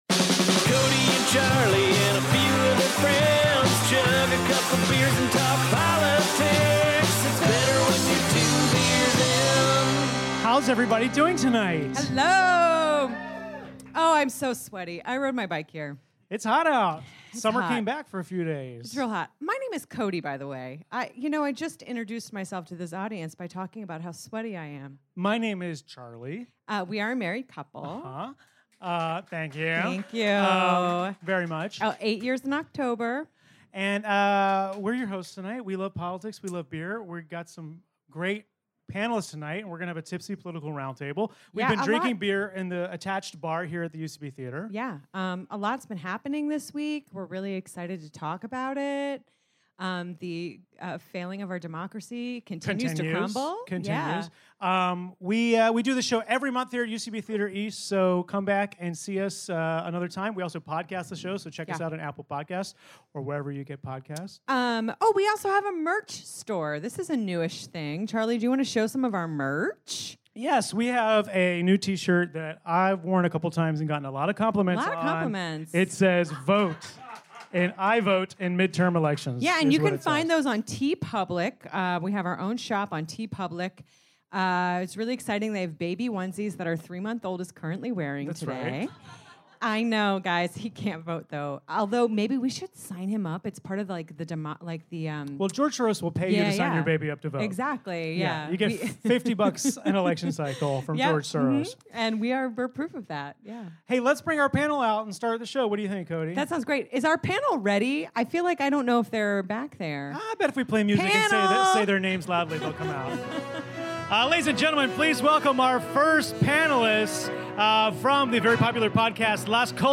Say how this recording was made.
live from the UCB Theatre East Village.